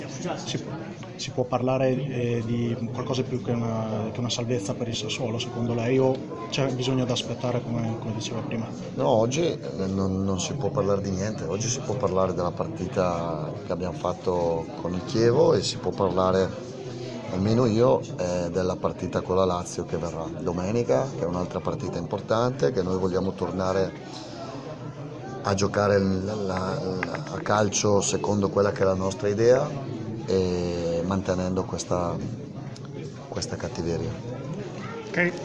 Dopo Chievo-Sassuolo abbiamo fatto una domanda a Roberto De Zerbi, allenatore del Sassuolo.
INTERVISTA-DE-ZERBI.mp3